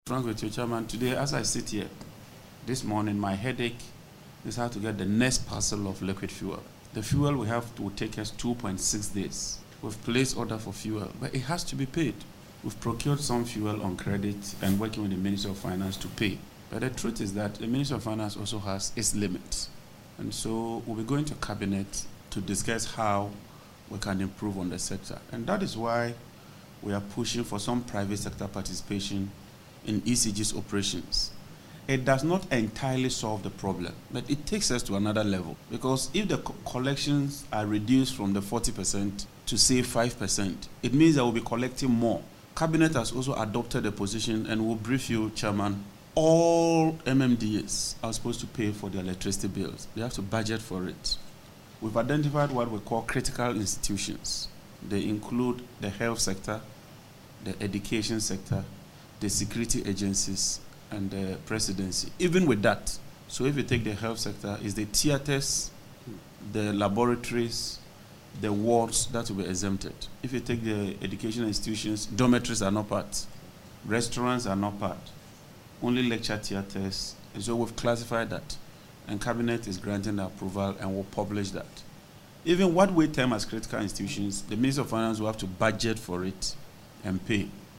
Addressing the Parliamentary Committee on Energy on Thursday, May 15, Mr Jinapor stated that the Ministry is racing against time to secure billions of cedis needed to pay for new fuel supplies already ordered.